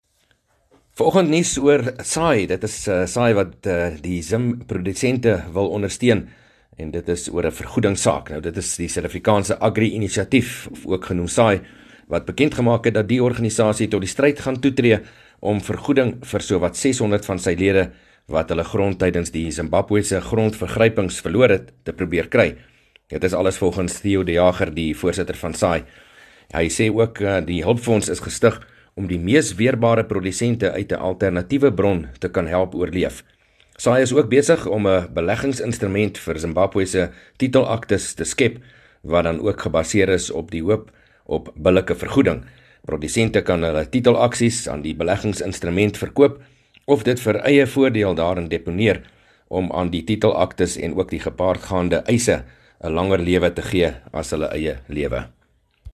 22 May PM berig oor ‘n inisiatief van SAAI om Zimbabwe-boere te help